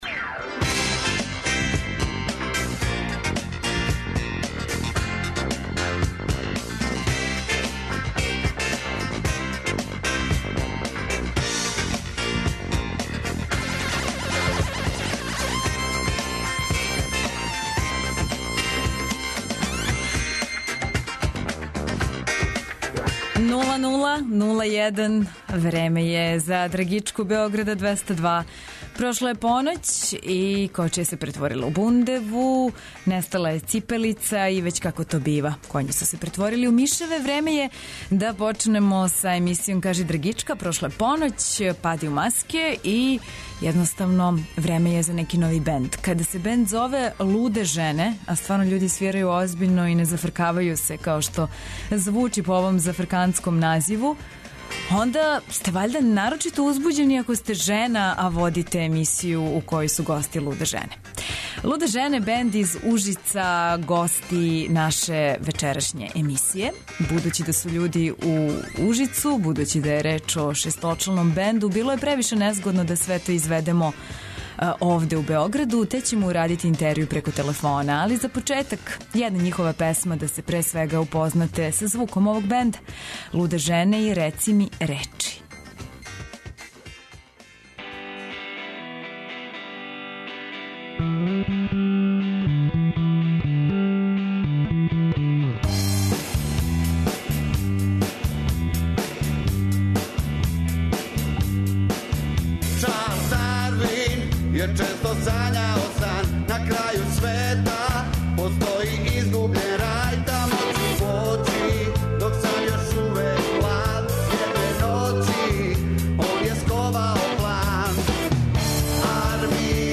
Вечерас нам у госте долазе 'Луде жене'.